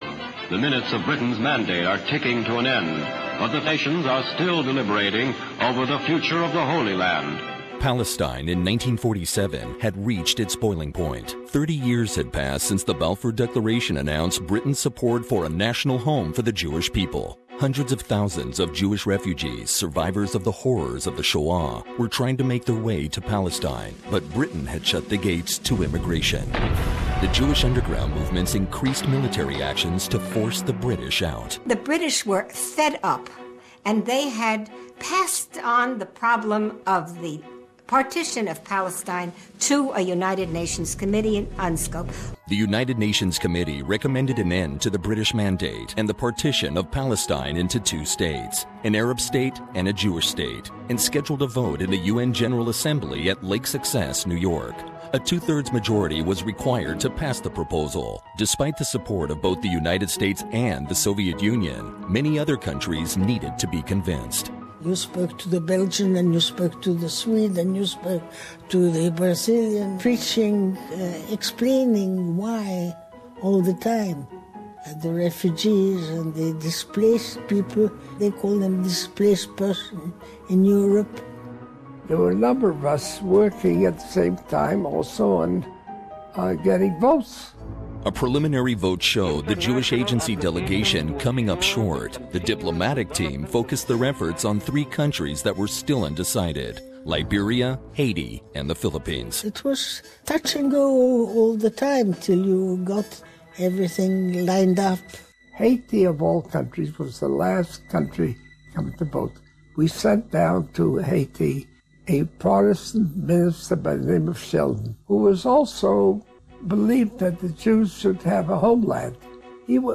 Don't miss a special eyewitnesses report from people that were involved in the historical UN Vote of 29th November 1947!